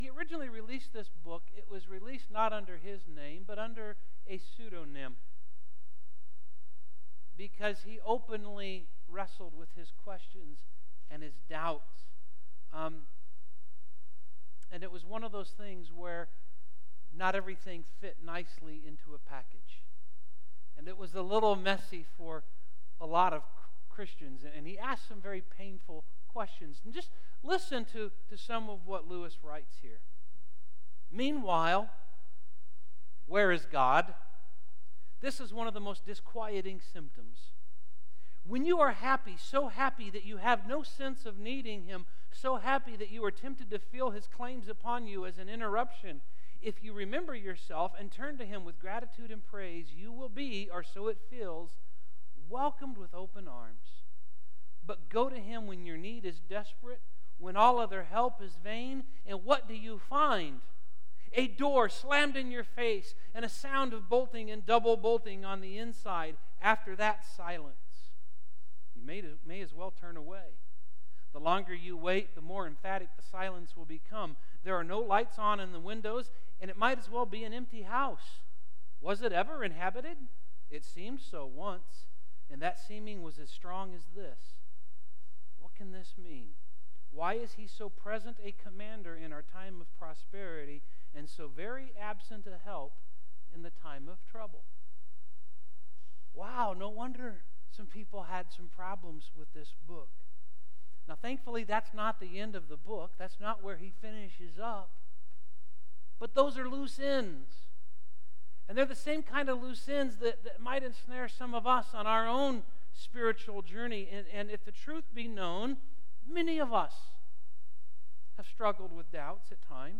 Asking God Why July 5, 2015 Faith , Hurting , Pain , Sorrow , Suffering A Safe Place for Hurting People Audio Sermon Save Audio Save PDF Questions and God Life is full of questions.